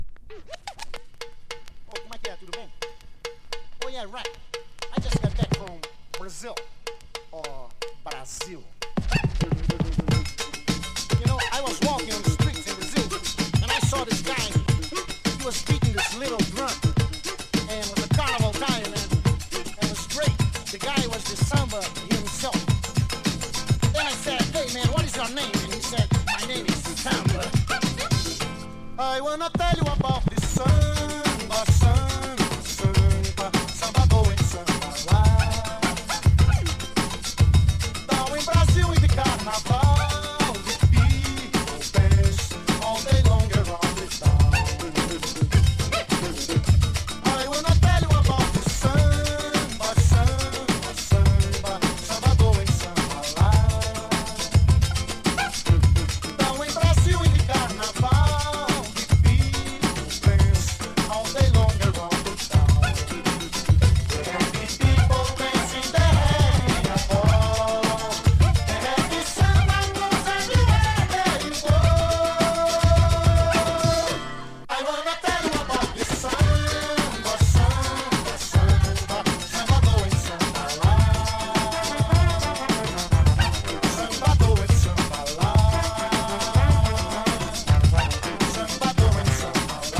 陽気なサンバネタ
BREAK BEATS / BIG BEAT